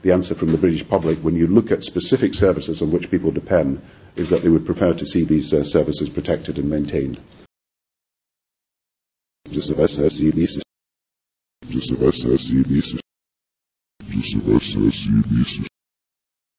Press Conference with British PM Gordon Brown